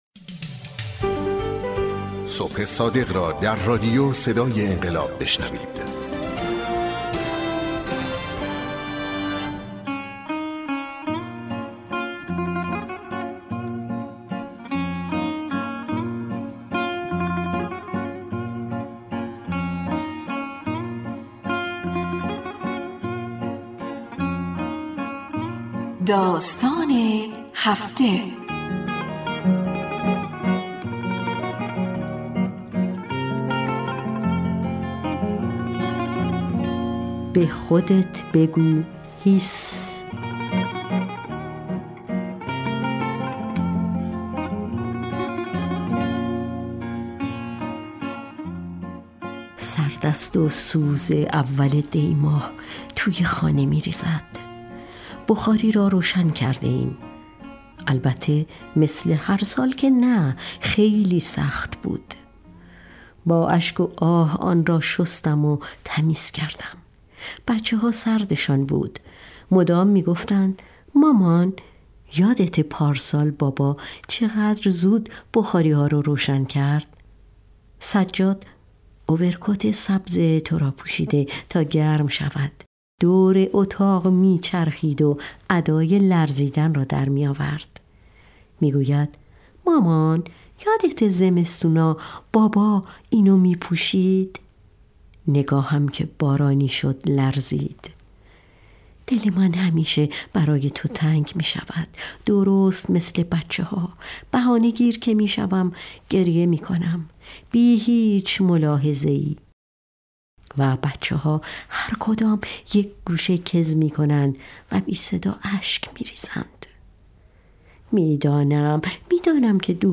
برچسب ها: بصیرت ، صدای انقلاب ، داستان ، شهید